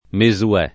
Mesuè [ me @ u- $+ ]